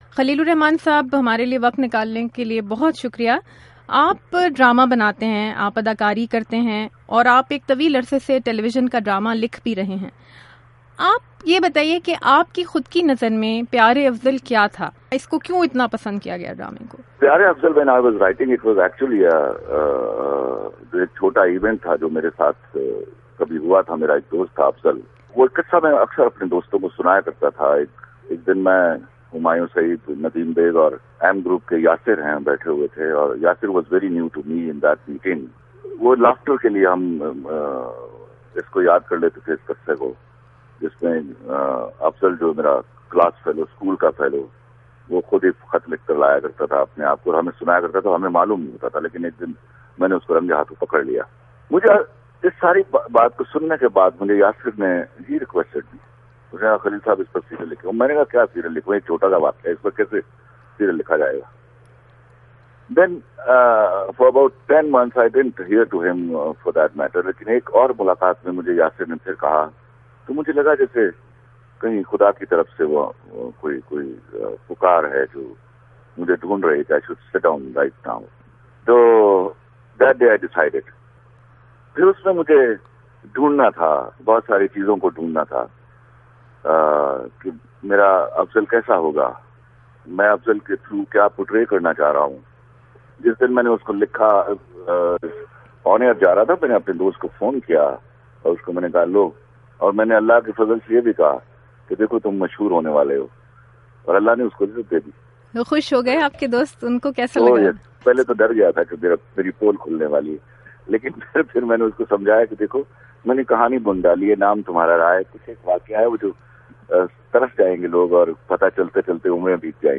پیارے افضل کے مصنف خلیل الرحمنٰ قمر کا انٹرویو